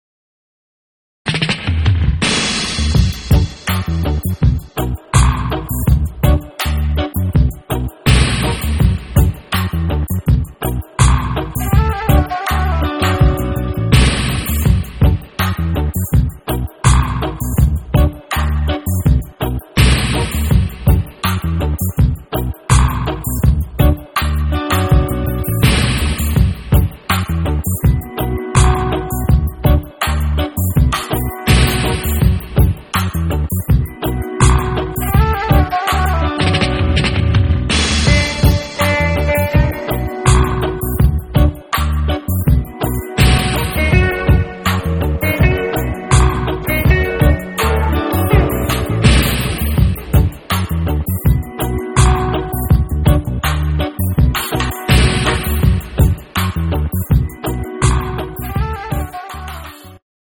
Electronic
Funk
Instrumental
Psychedelic
World Rhythms